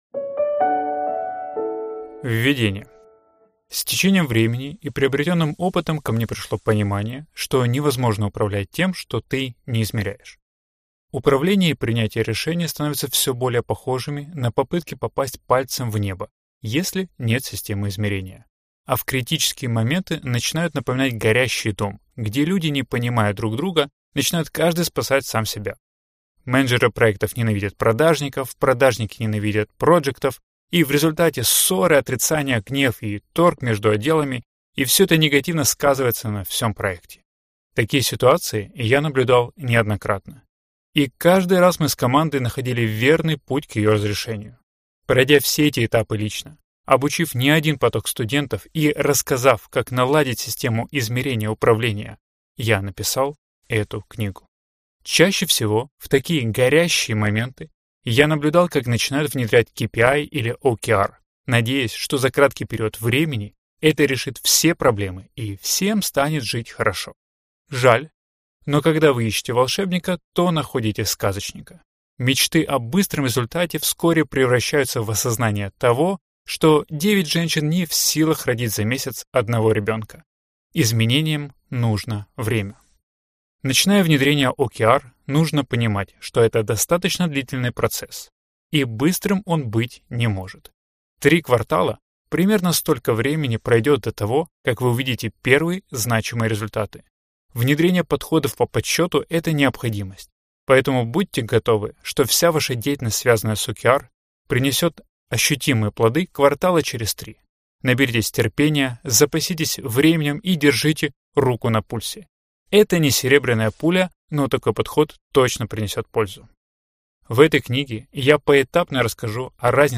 Аудиокнига Порядок в Хаосе. Objective and Key Results (OKR) | Библиотека аудиокниг